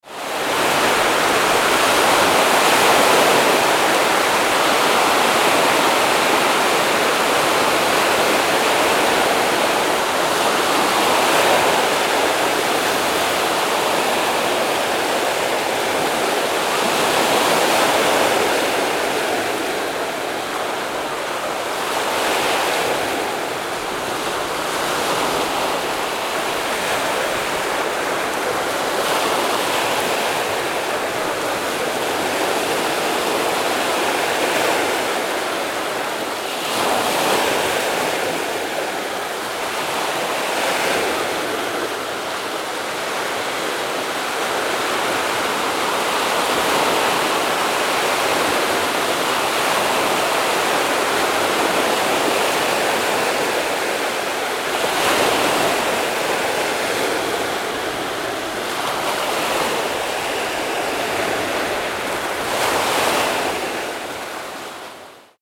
Ocean Waves Ambience Sound: Natural White Noise
Description: Ocean waves ambience sound effect. Capture the atmosphere of sea waves ambience with natural white noise.
Bring the coast to life with clean and immersive wave recordings.
Genres: Sound Effects
Ocean-waves-ambience-sound-effect.mp3